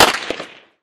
light_crack_09.ogg